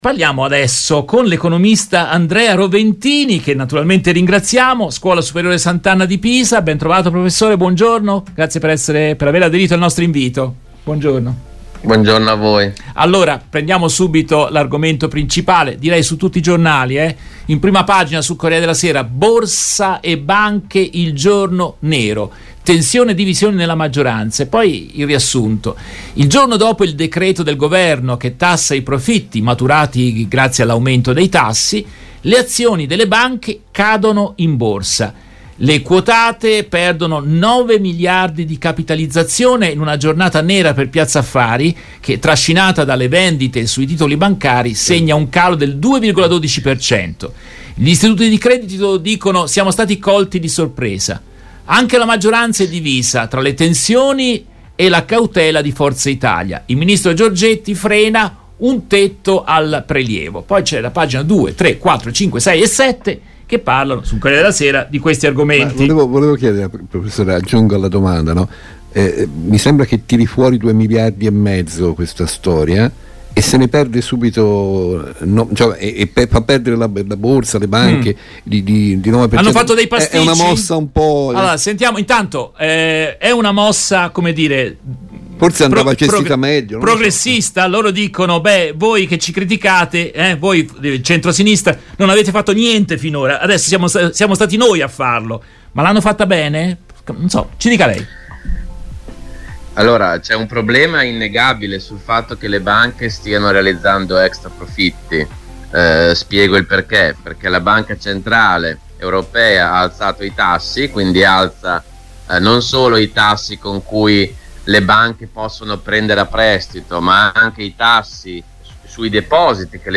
In questa intervista tratta dalla diretta RVS del 09 agosto 2023